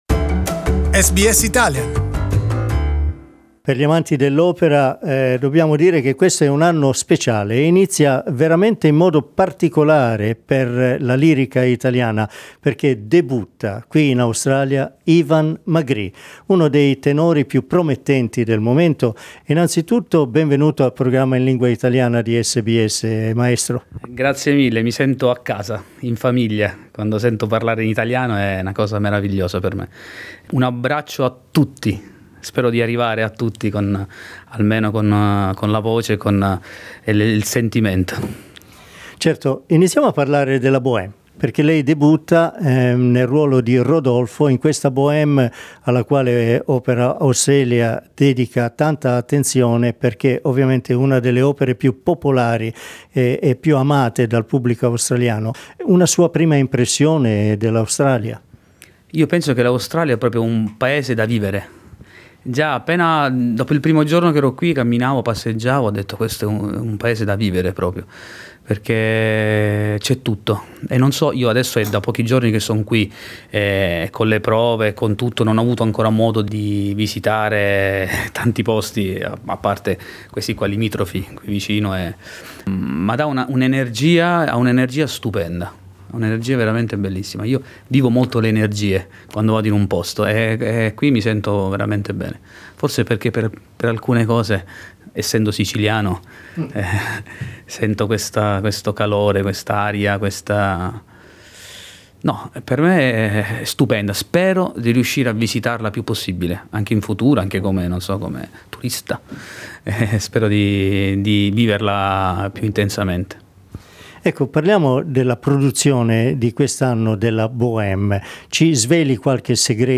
Con La Boheme di Giacomo Puccini ha avuto inizio ufficialmente la stagione lirica 2019 di Opera Australia. Il tenore